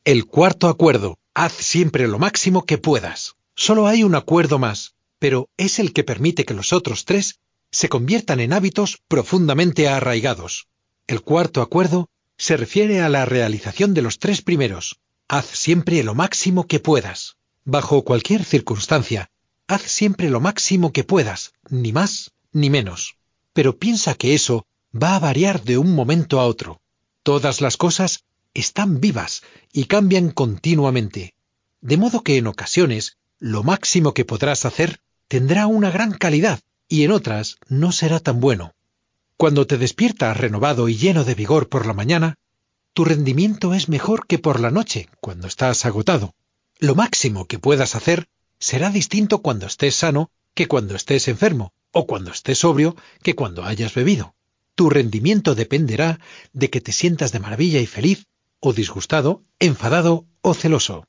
audiolibro Los cuatro acuerdos Una guia practica para la libertad personal Janet Mills